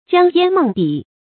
江淹夢筆 注音： ㄐㄧㄤ ㄧㄢ ㄇㄥˋ ㄅㄧˇ 讀音讀法： 意思解釋： 傳說南朝梁江淹夜夢郭璞索還五色筆，爾后為詩遂無佳句。